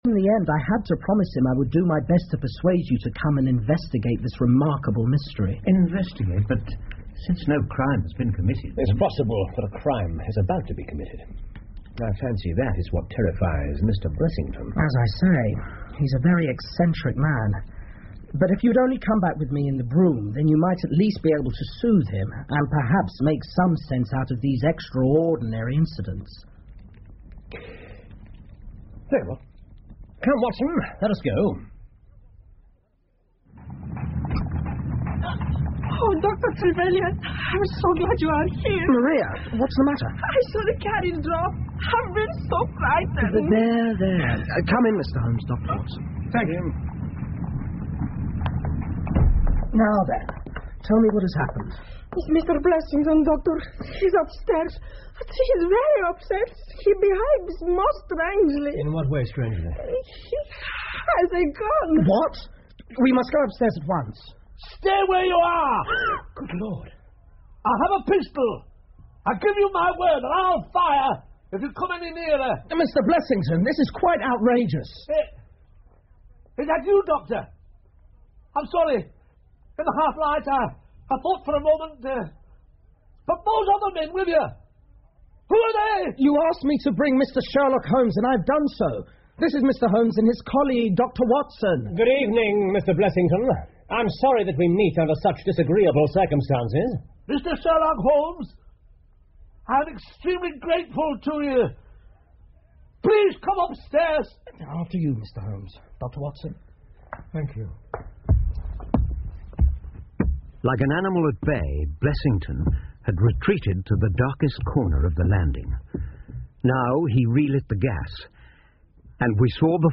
福尔摩斯广播剧 The Resident Patient 5 听力文件下载—在线英语听力室